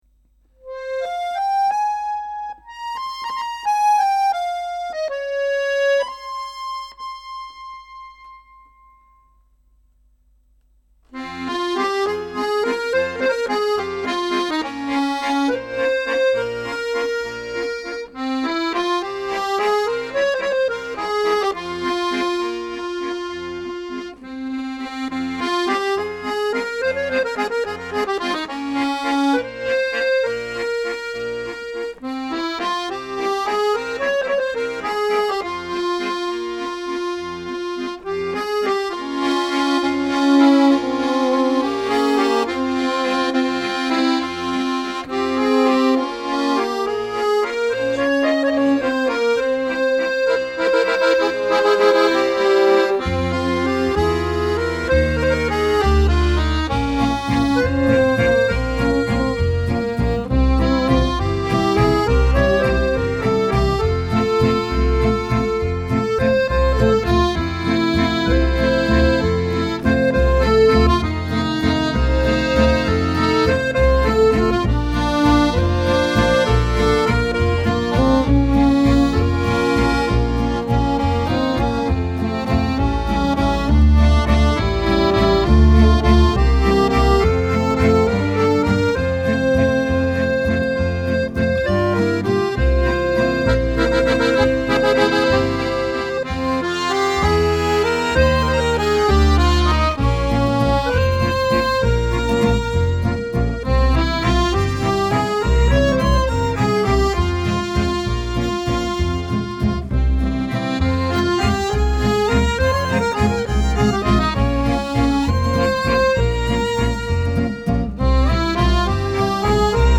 ROMANTISCHE WALS